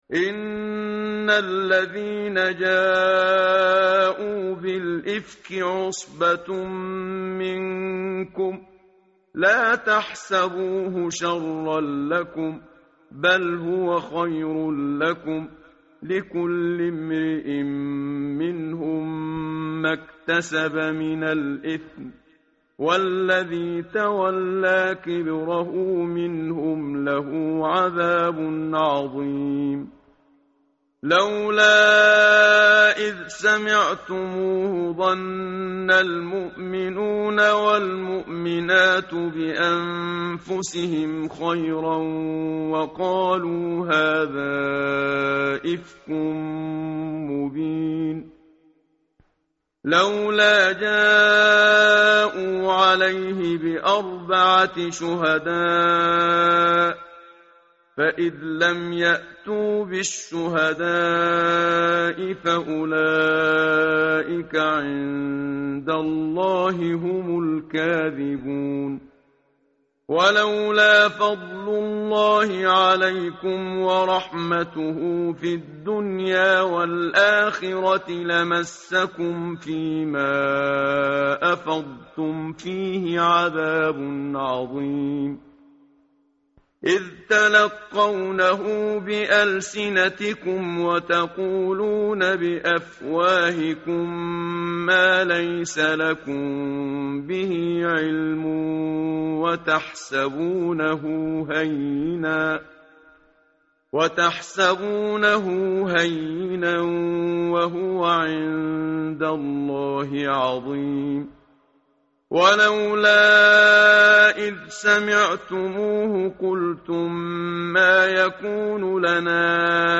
متن قرآن همراه باتلاوت قرآن و ترجمه
tartil_menshavi_page_351.mp3